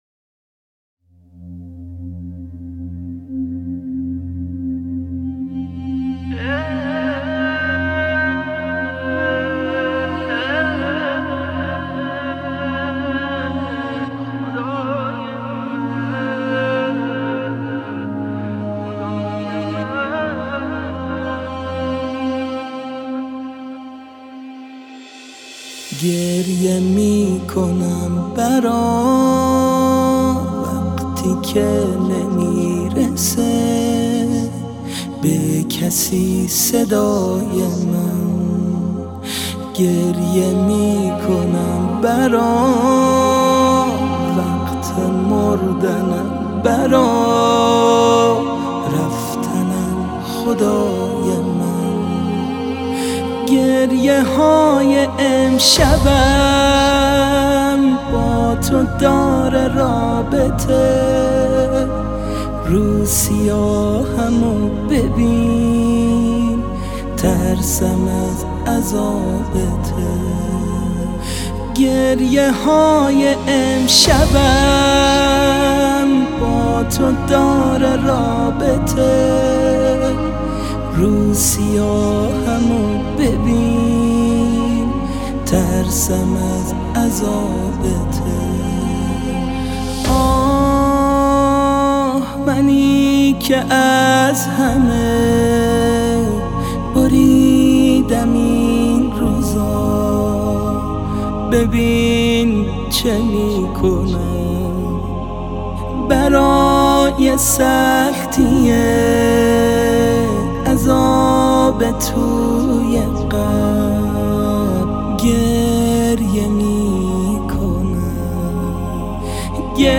با صدای دلنشین
مناجات با خدا